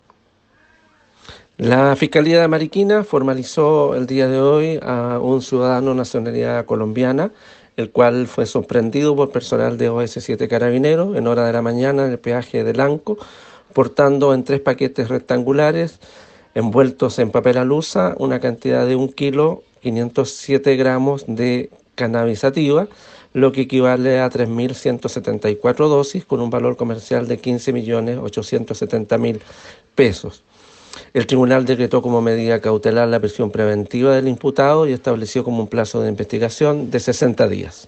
fiscal Alejandro Ríos